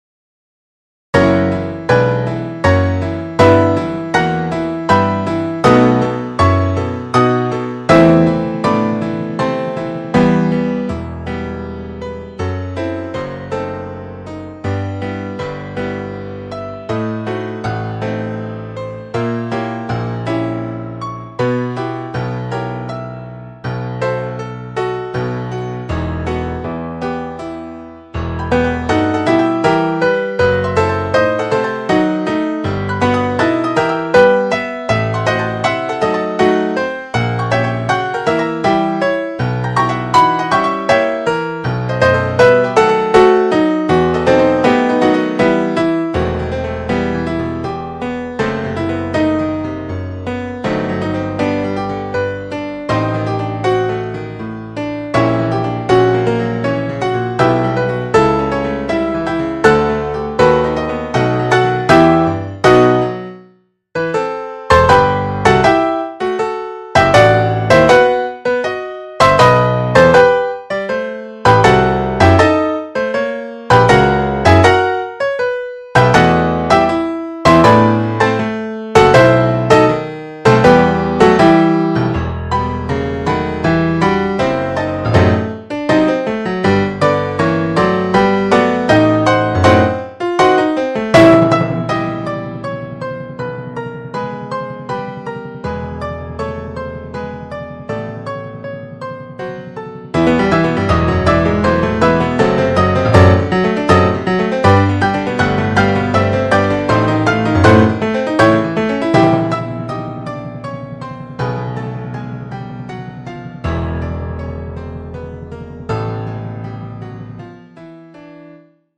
MIDI Practice Tracks:
*These were created using MIDI with the first violin part removed. I intensely dislike the midi orchestral instrument sounds, so I changed all the instruments to the piano patch. Caution: the first chord is rather loud.
Quarter Note = 80